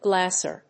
/ˈglæsɝ(米国英語), ˈglæsɜ:(英国英語)/